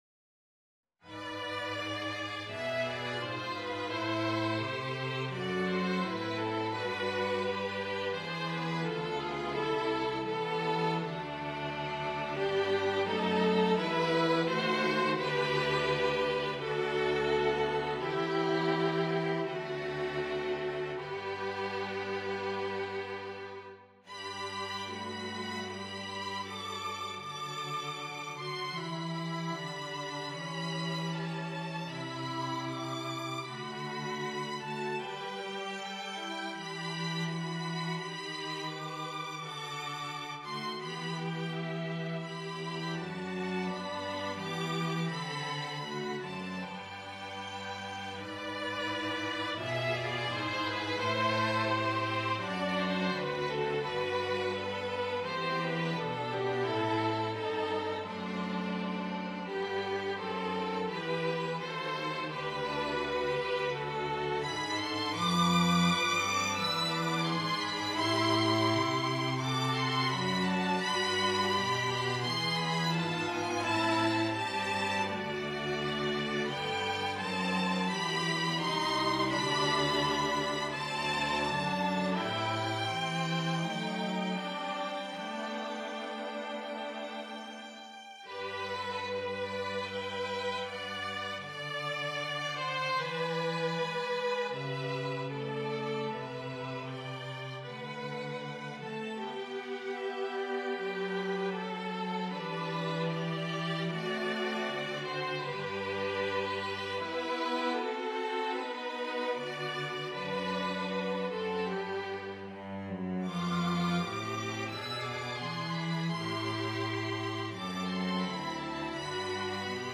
Adapted for String Quartet